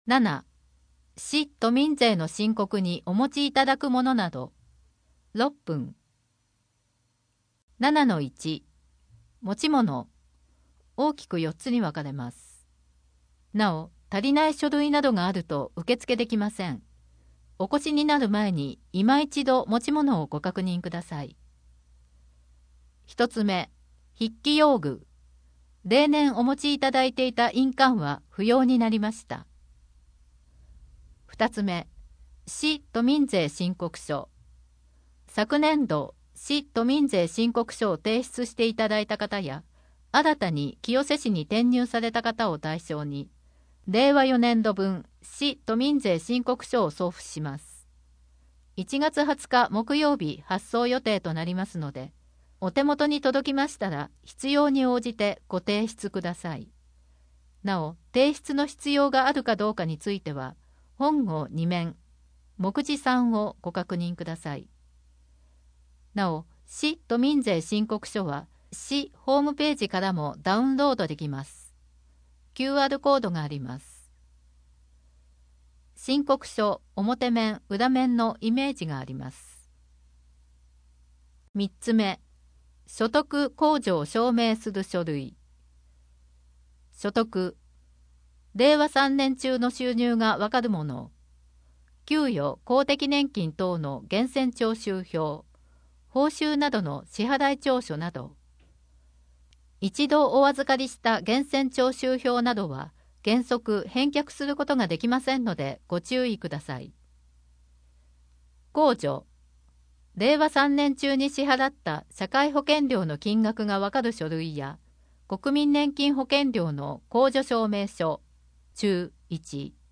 チェック表の結果「確定申告」が必要になった方 3面 チェック表の結果「市・都民税の申告」が必要になった方 郵送での提出にご協力ください 2022年1月15日税の申告特集号2・3面PDF （PDF 5.1 MB） 4面 市・都民税申告にお持ちいただくものなど 医療費控除について 医療費のお知らせ（医療費等通知書）は医療費控除に使用できます] 要介護認定等をお持ちの方へ～ 医療費控除・障害者控除の対象ではありませんか？ 2022年1月15日税の申告特集号4面PDF （PDF 934.2 KB） 声の広報 声の広報は清瀬市公共刊行物音訳機関が制作しています。